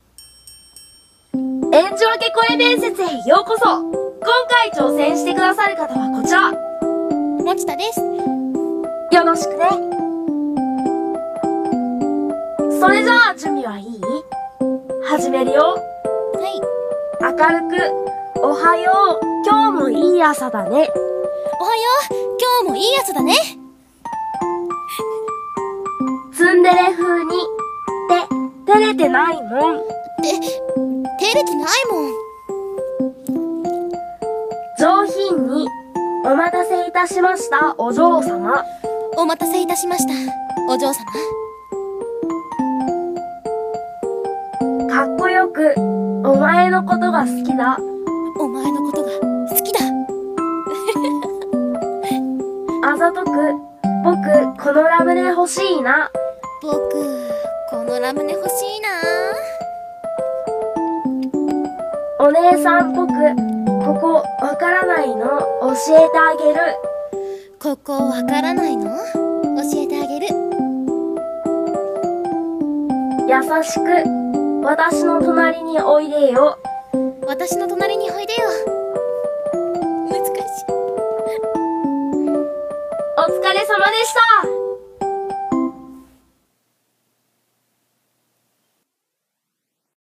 【多声類用】演じ分け声面接